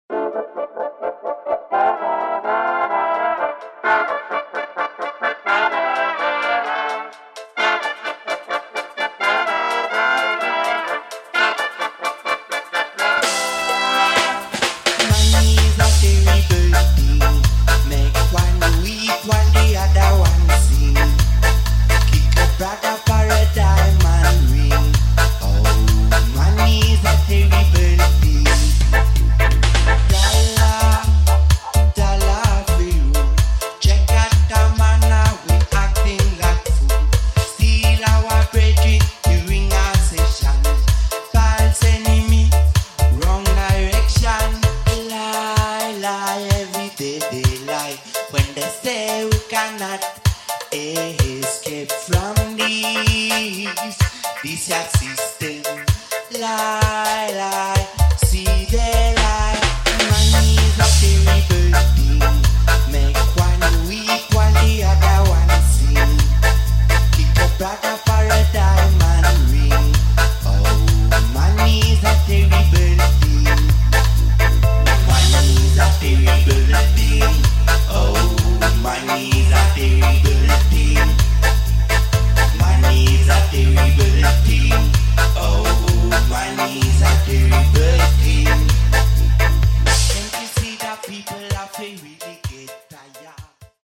[ DUB / REGGAE / JUNGLE ]